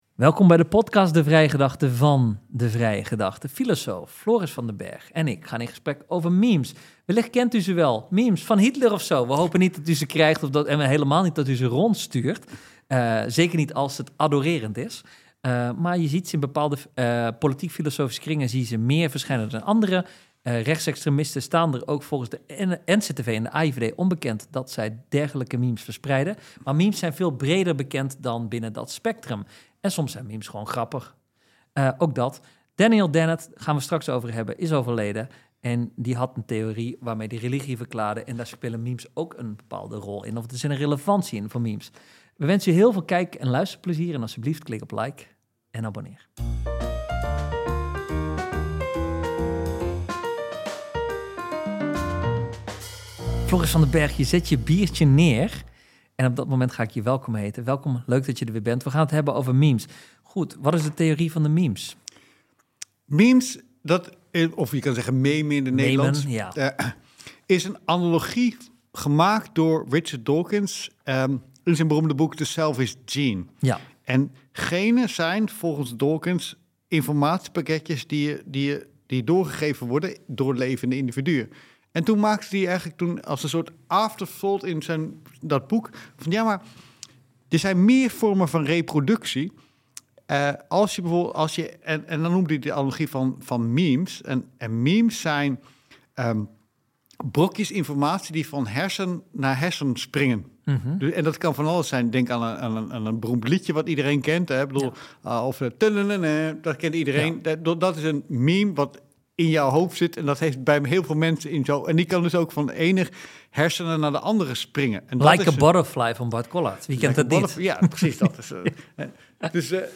Deze podcasts zijn opgenomen met beeld.